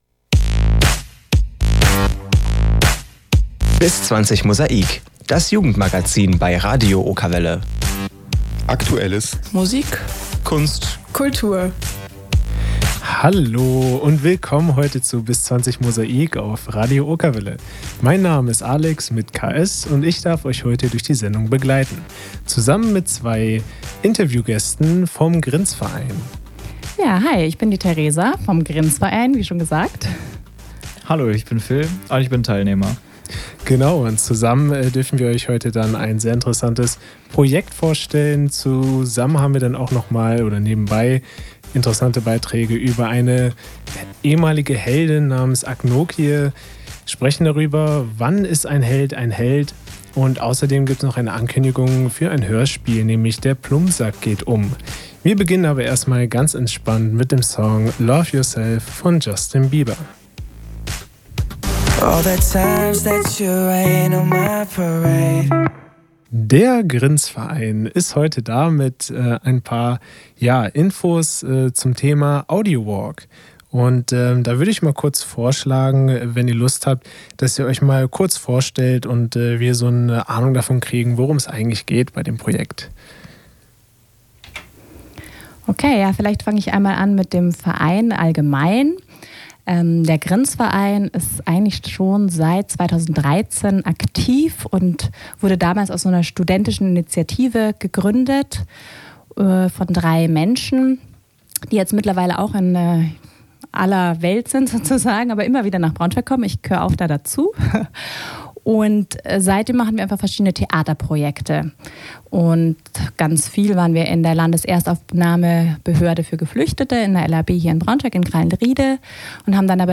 Radio Okerwelle, Interview zum Projekt „stadt.sehen.hören.“